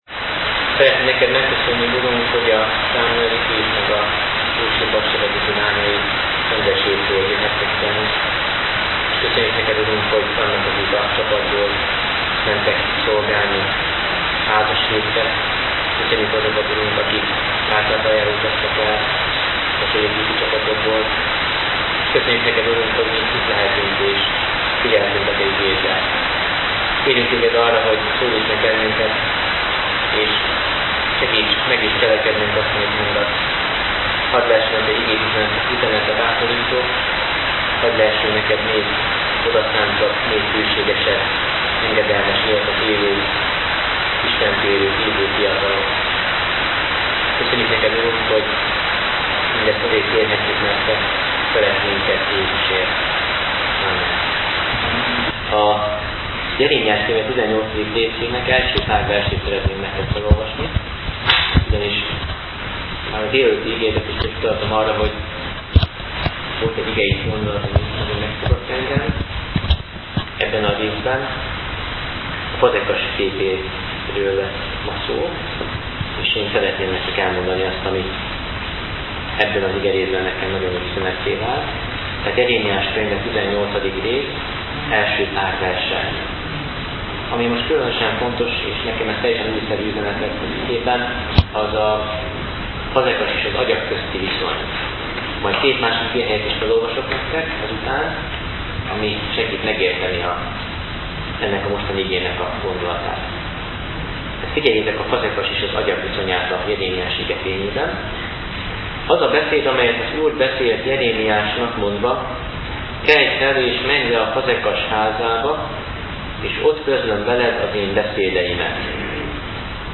A háttérzaj az épp tomboló viharnak köszönhet?.